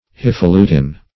hifalutin - definition of hifalutin - synonyms, pronunciation, spelling from Free Dictionary
Hifalutin \Hi`fa*lu"tin\, n.